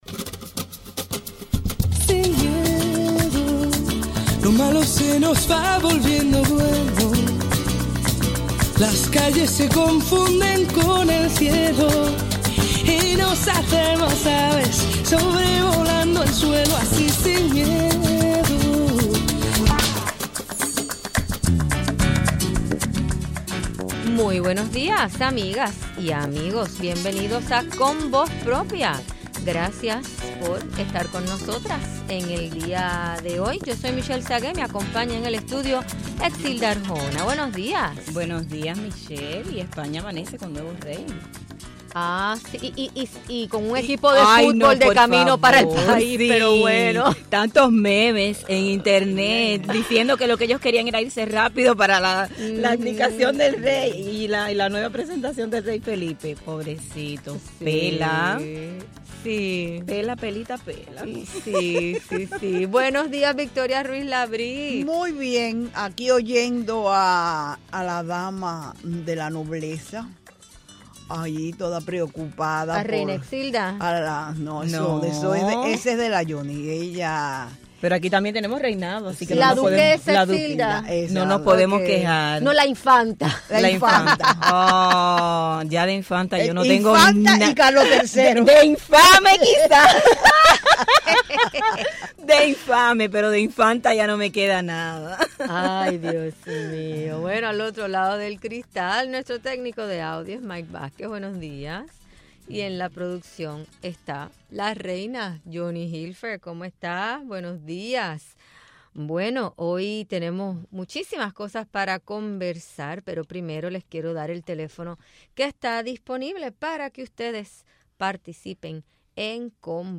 En nuestros estudios